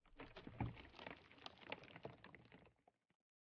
Minecraft Version Minecraft Version 1.21.5 Latest Release | Latest Snapshot 1.21.5 / assets / minecraft / sounds / block / pale_hanging_moss / pale_hanging_moss1.ogg Compare With Compare With Latest Release | Latest Snapshot
pale_hanging_moss1.ogg